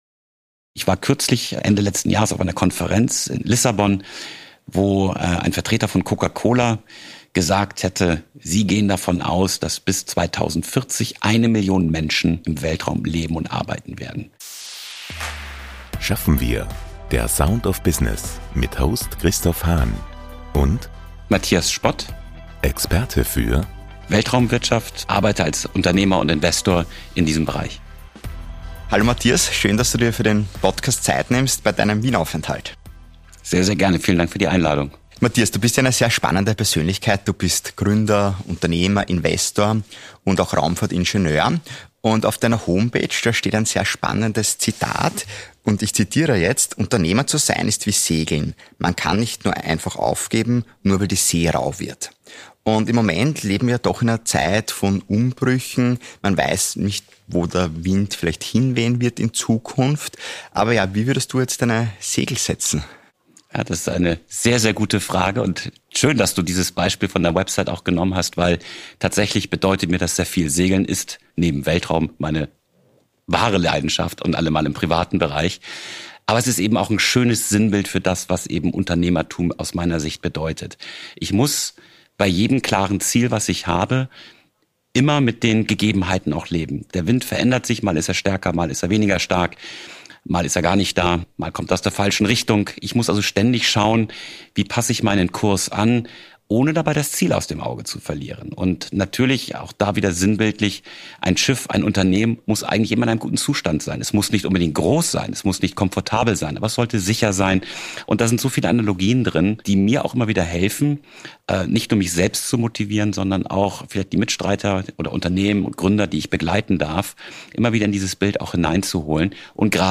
Heute im Gespräch